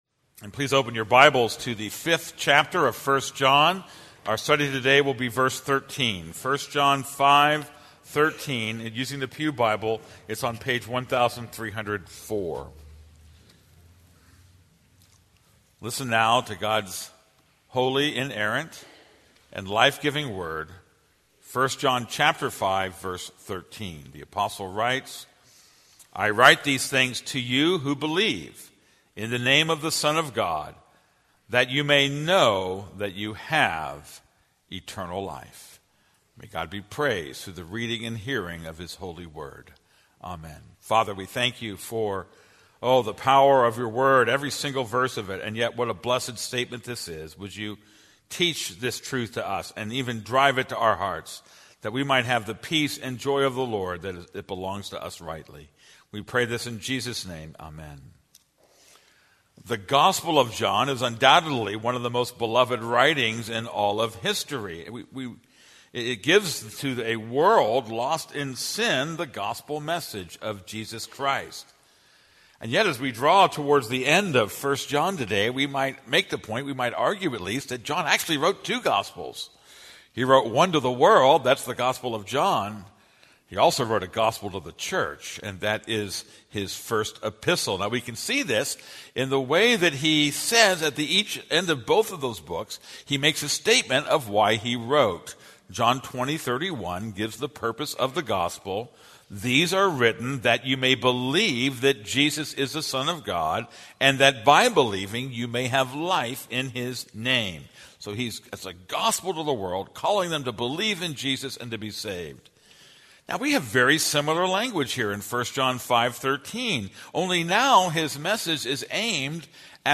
This is a sermon on 1 John 5:13.